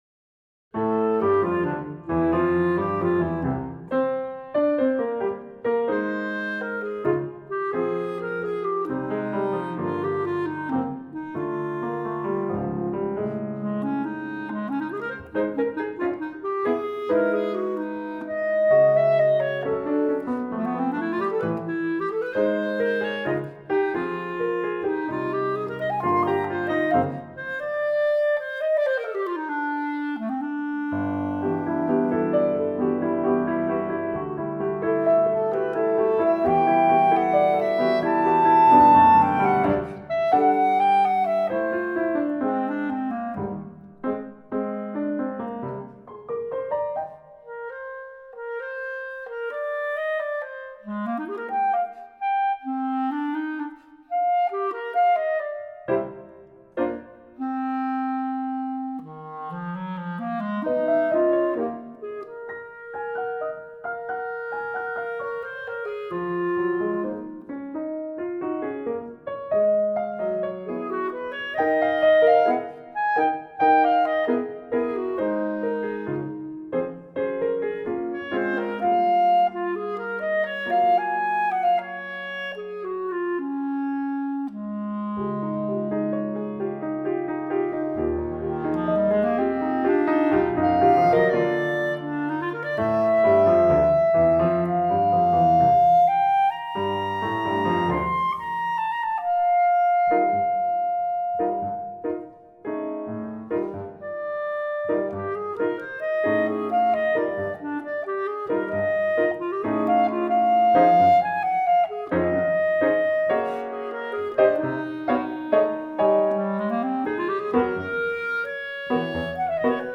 This lively composition
clarinet, piano